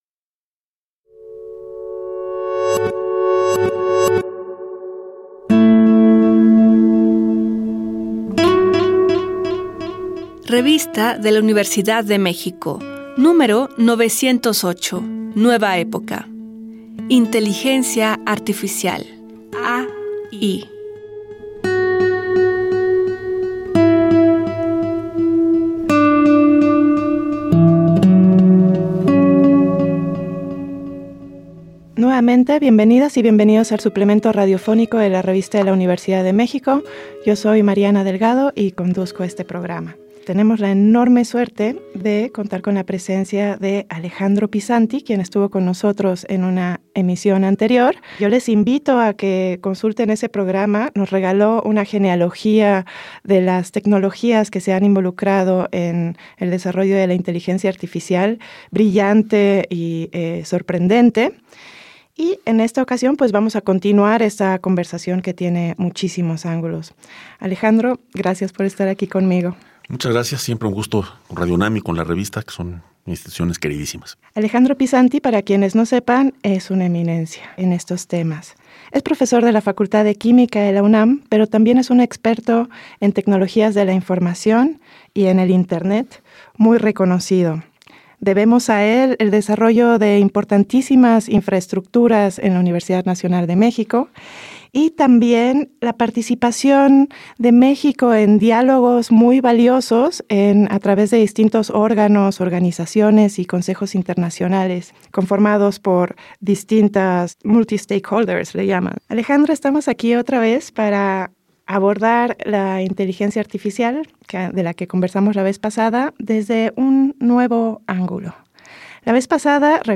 Cargar audio Este programa es una coproducción de la Revista de la Universidad de México y Radio UNAM. Fue transmitido el jueves 16 de mayo de 2024 por el 96.1 FM.